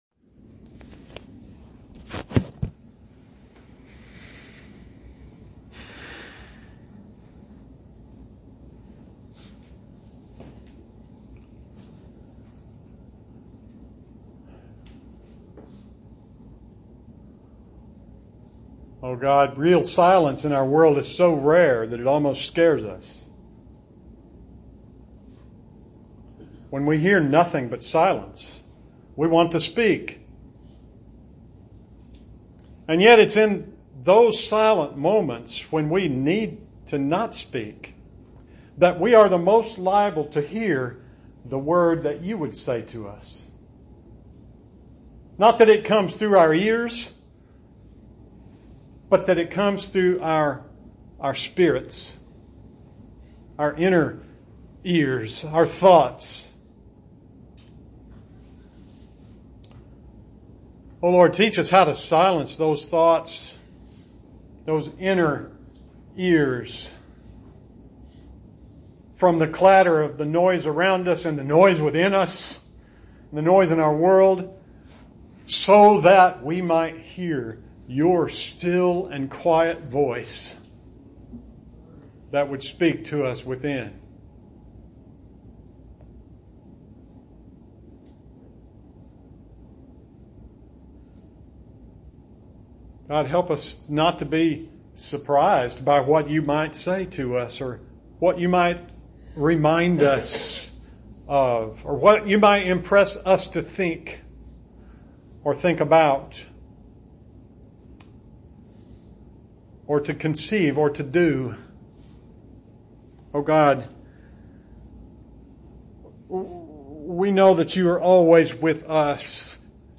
August 18, 2019 – 8:45 Service